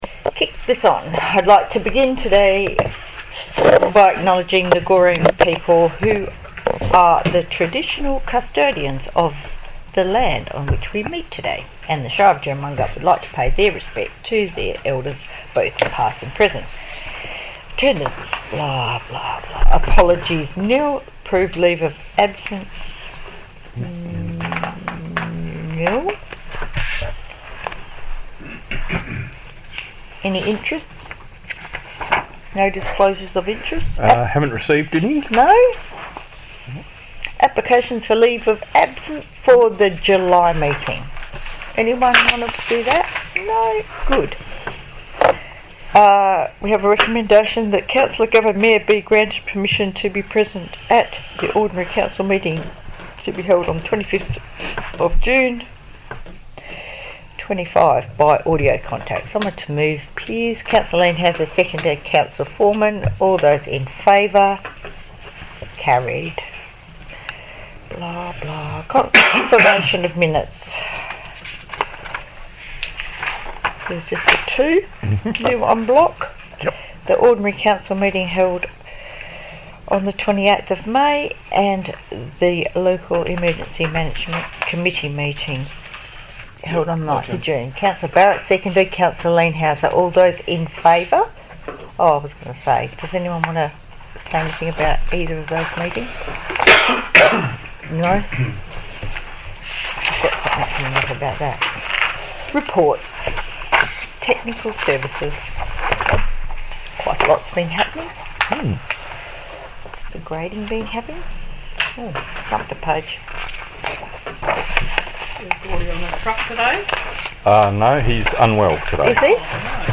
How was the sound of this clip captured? Ordinary Council Meeting - 25 June 2025 - Recording (16.03 MB)